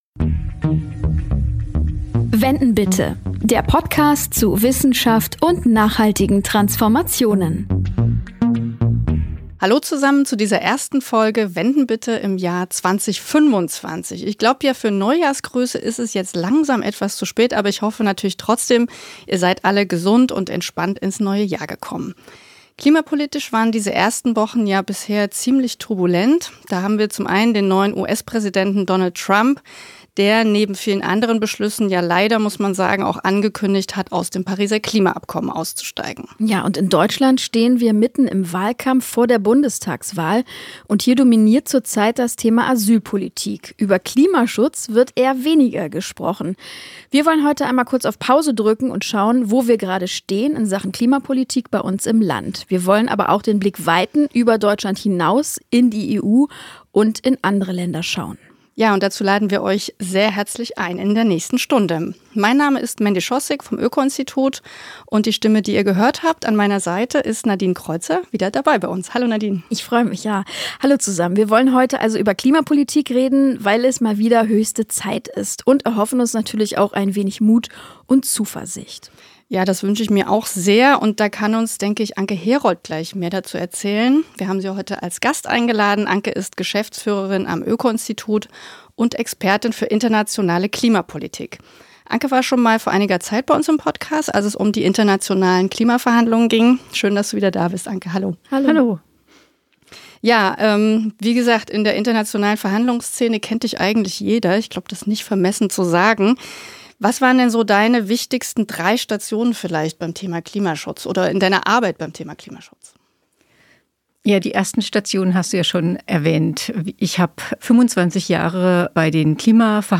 Wir interviewen Wissenschaftler*innen des Öko-Instituts und fragen, wo ein Thema aus Nachhaltigkeitssicht aktuell steht, welche Herausforderungen es auf dem Weg zu mehr Umwelt-, Klima- und Ressourcenschutz gibt und wie die Zukunft gestaltet werden kann.